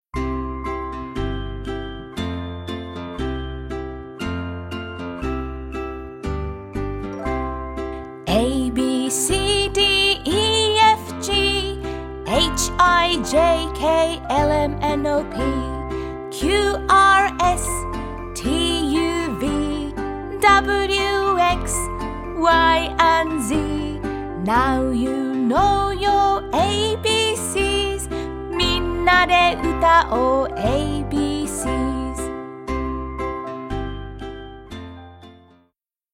Actress, young, excitable, versatile.
Japanese and English Characters